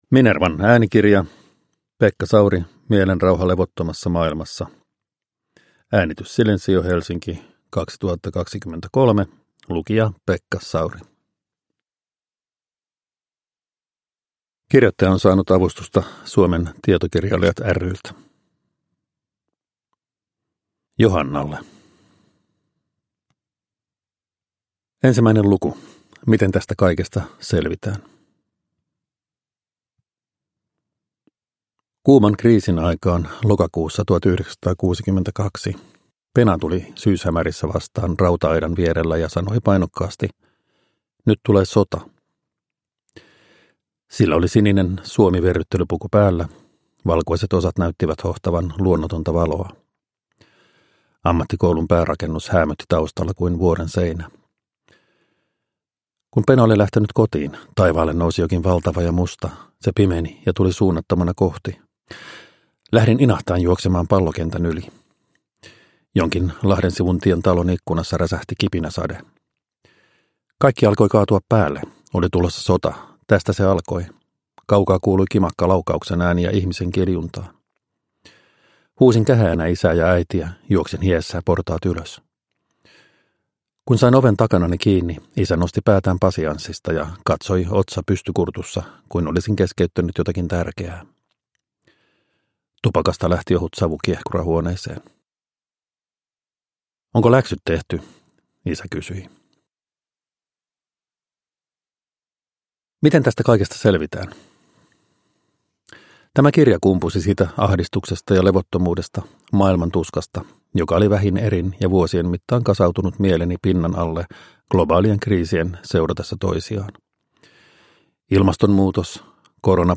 Uppläsare: Pekka Sauri